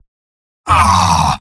Robot-filtered lines from MvM. This is an audio clip from the game Team Fortress 2 .
Spy_mvm_negativevocalization09.mp3